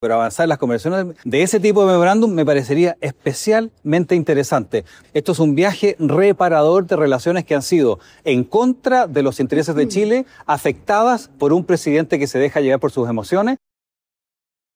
En tanto, el senador independiente Rojo Edwards planteó que José Antonio Kast debería aprovechar el viaje para ir más allá y explorar preliminarmente acuerdos con Estados Unidos en áreas como los minerales estratégicos.